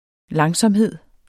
Udtale [ ˈlɑŋsʌmˀˌheðˀ ]